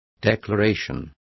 Complete with pronunciation of the translation of declaration.